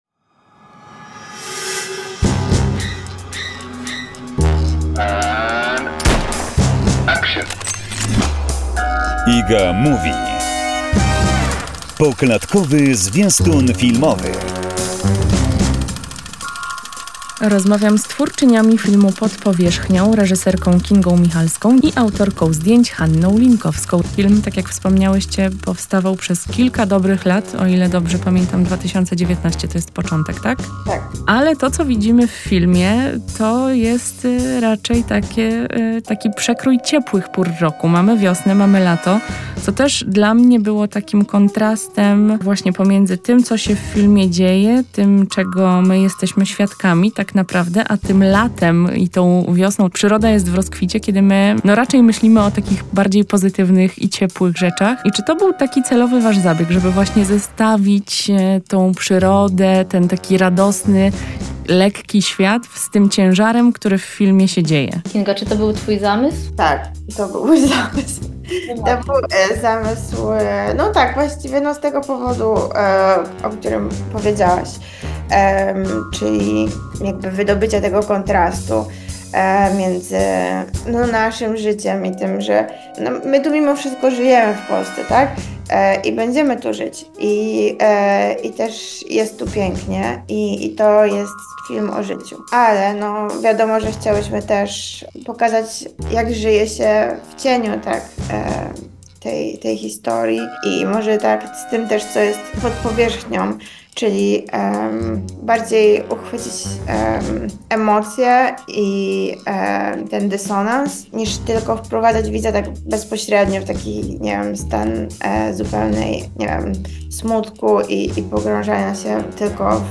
rozmowa o filmie „Pod powierzchnią” cz.3